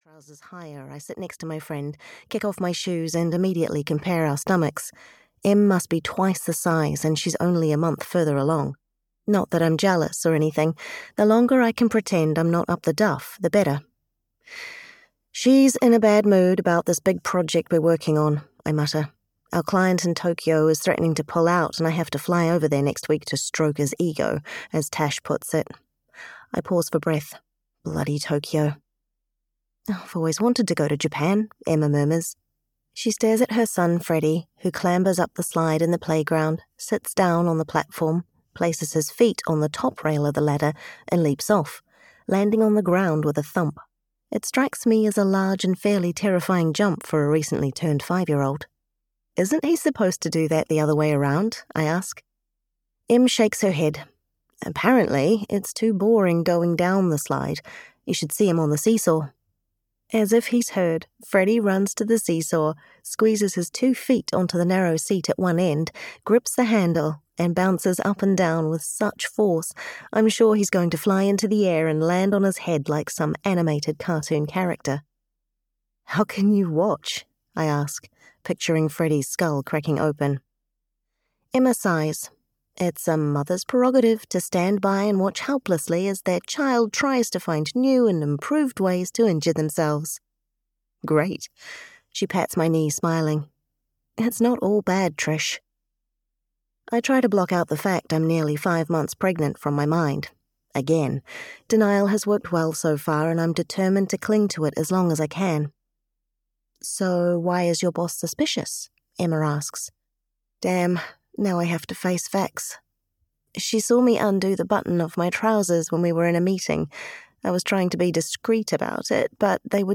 A Bumpy Year (EN) audiokniha
Ukázka z knihy